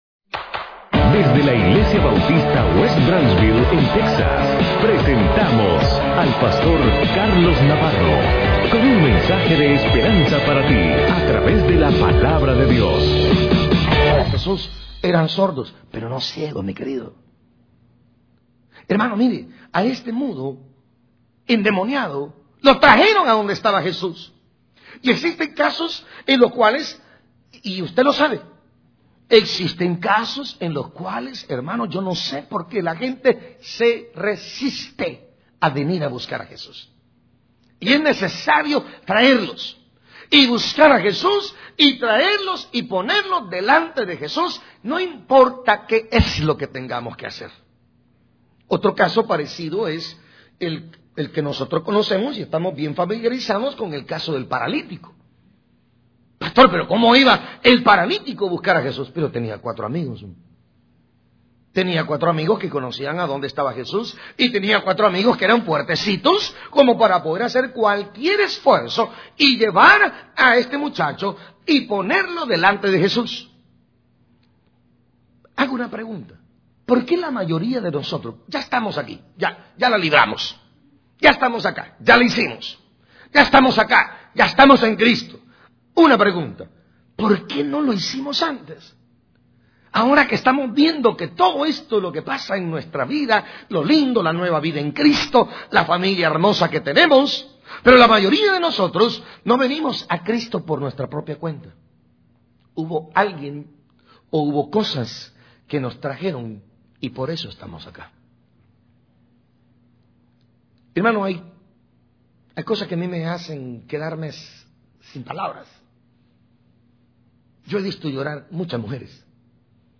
Predicador